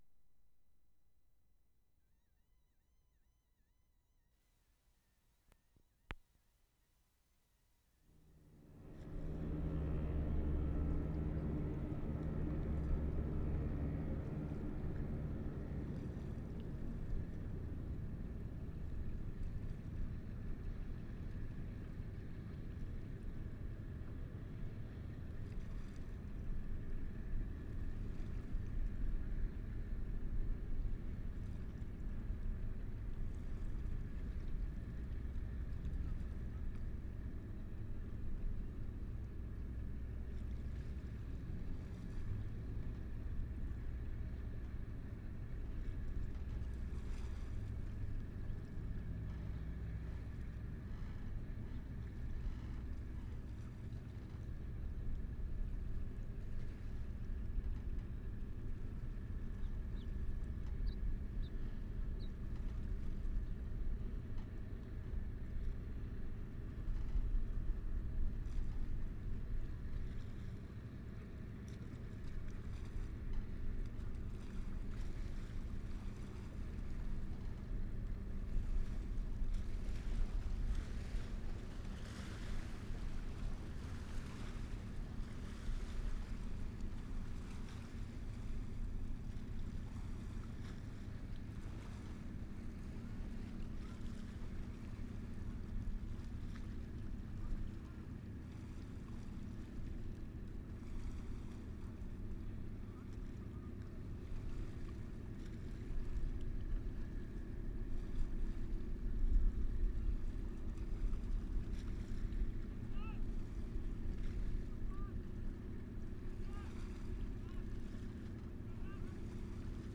WATERFRONT / CP WHARF Sept. 13, 1972
HARBOUR AMBIENCE 3'40"
2. Starts with seaplane in progress. Mostly water sounds in foreground and general city hum.